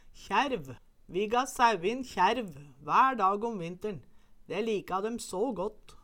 Høyr på uttala Ordklasse: Substantiv inkjekjønn Attende til søk